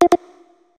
notification_sounds
dutdut.ogg